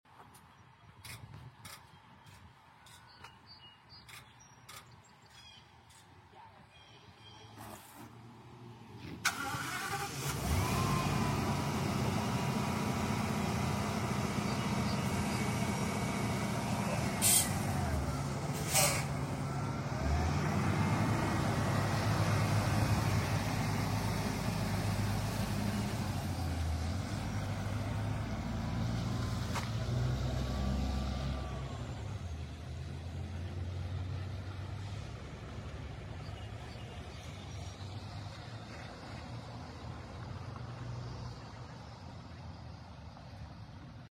2016 bluebird vision start up sound effects free download
2016 bluebird vision start up + leave